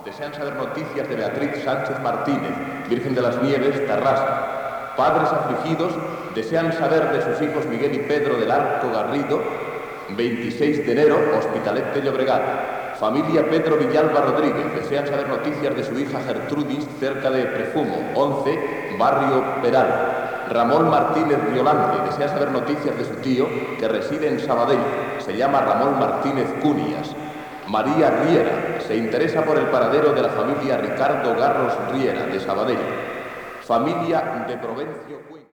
Missatges de persones per intentar trobar familiars desaparegudes a les riuades del Vallès.